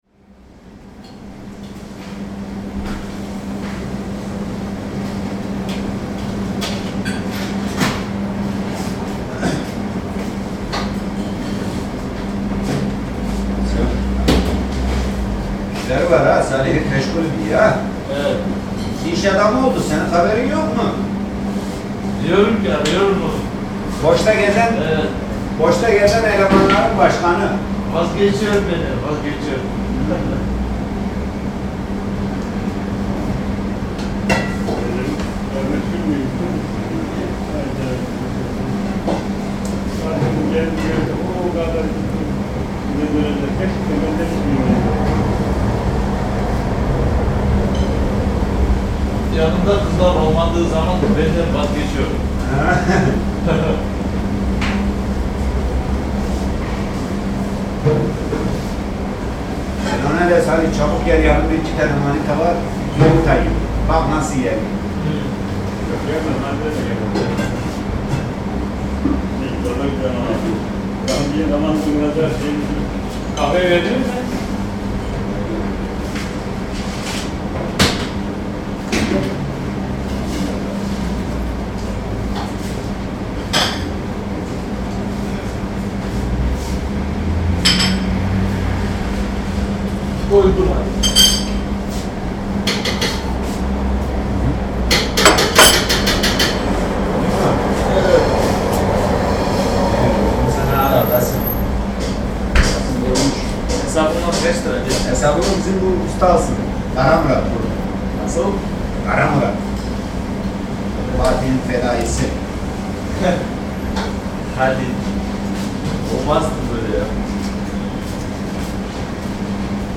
Talk in Turkish bistro | Zvuky Prahy / Sounds of Prague
Rozhovor V tureckém bistru
Turecký rozhovor v bistru Pasha Kebab v Jungmanově ulici.
interiéry lidé rozhovory jídelny